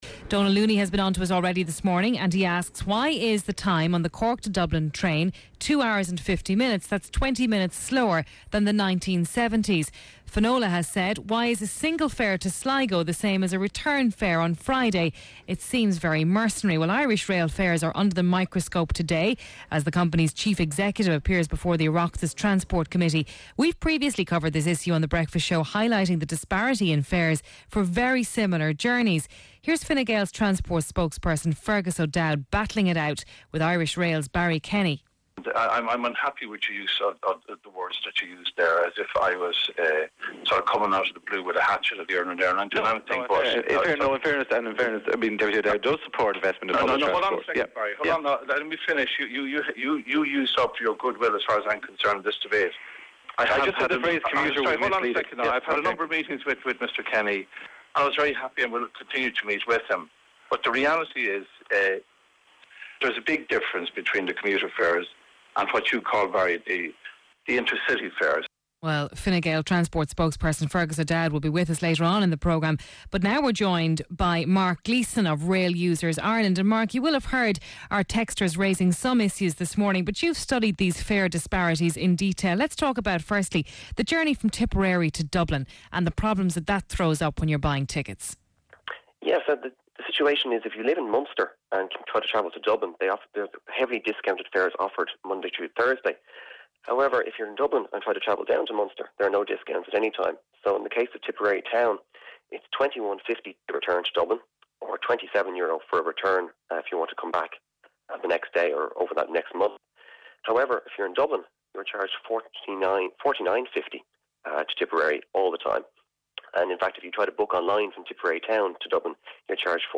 Claire Byrne is the interviewer.